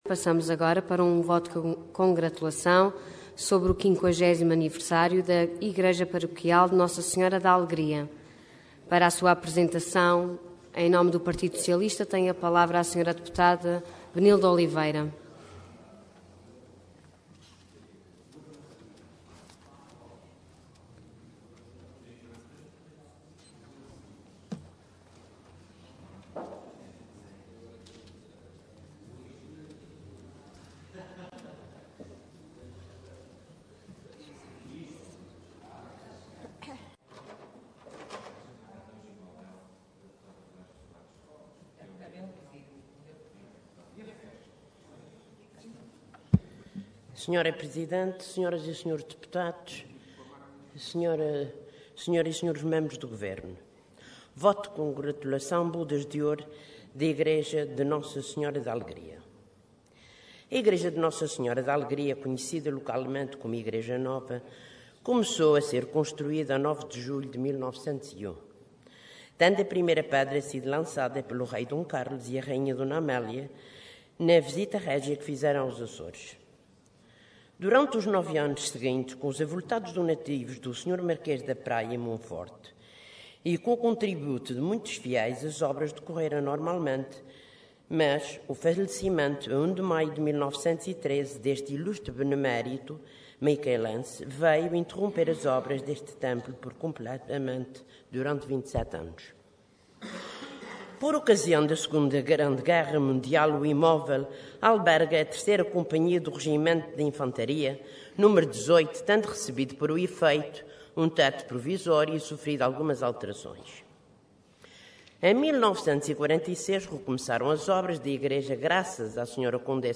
Detalhe de vídeo 10 de dezembro de 2013 Download áudio Download vídeo Processo X Legislatura 50º Aniversário da Igreja Paroquial de Nossa Senhora da Alegria Intervenção Voto de Congratulação Orador Benilde Oliveira Cargo Deputada Entidade PS